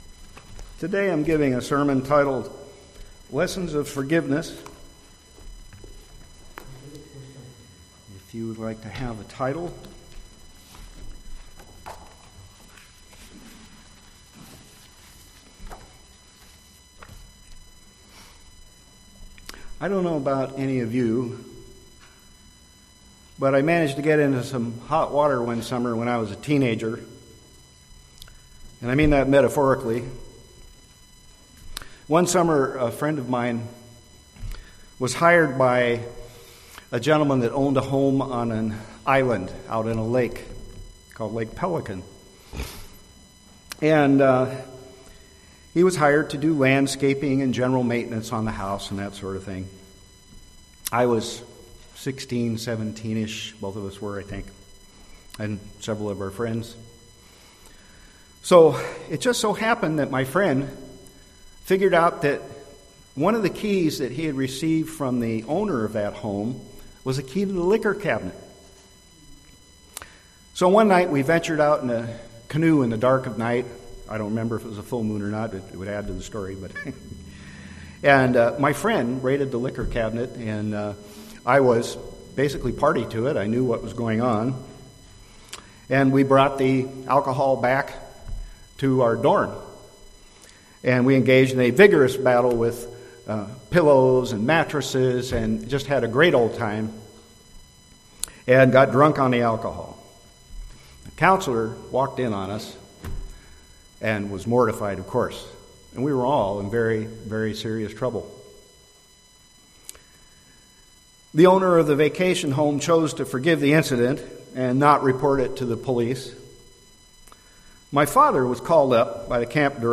Listen to this sermon to learn about seven lessons of forgiveness that will help you understand what God teaches us about forgiveness.